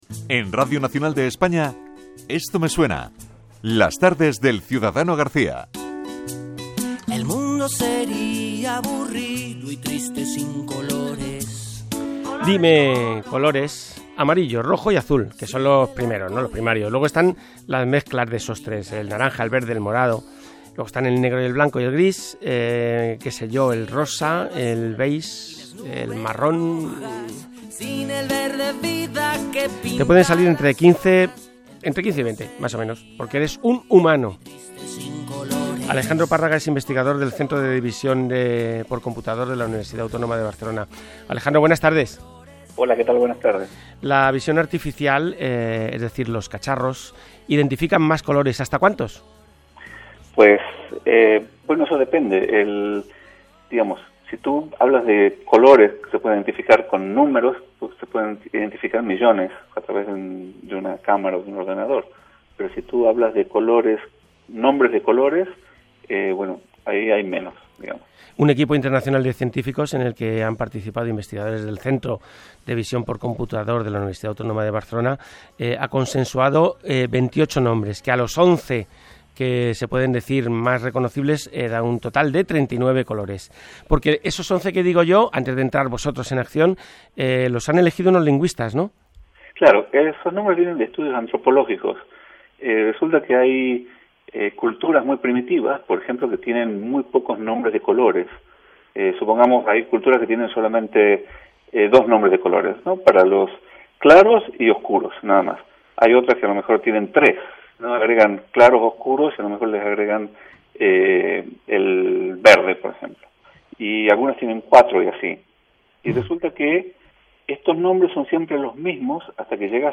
Interview in Spanish National Radio (RNE) check it out >